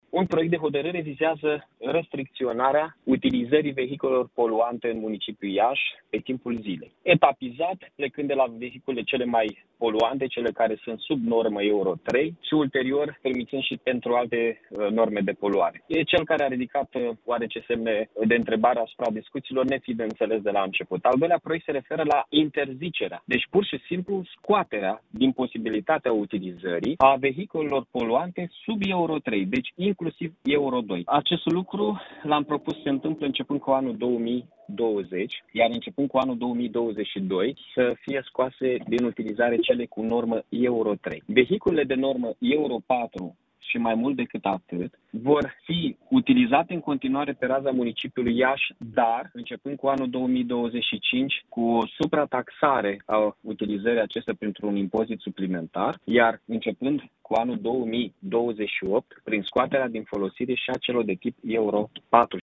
Primarul Mihai Chirica a explicat, pentru Radio Iaşi, care sunt aceste proiecte: